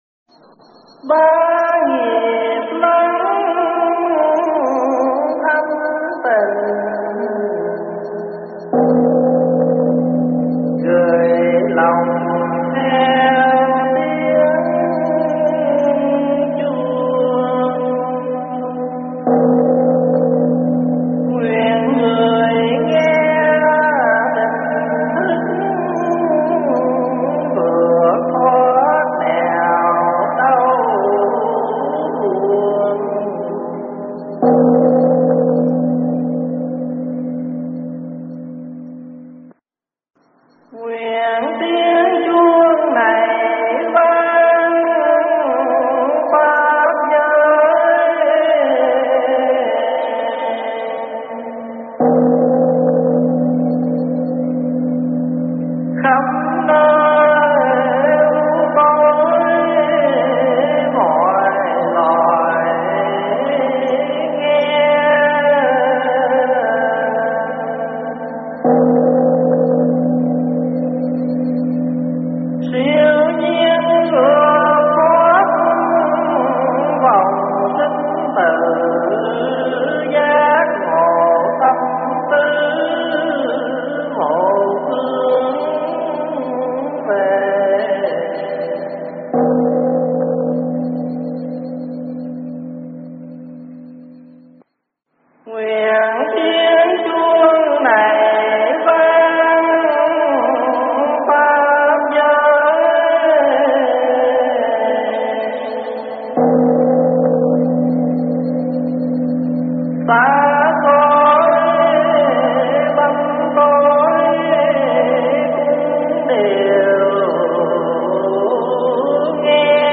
Nghe mp3 thuyết pháp Giảng Sư và Thánh Chúng do ĐĐ. Thích Pháp Hòa giảng tại Capri Hall, Vancouver ngày 1 tháng 7 năm 2011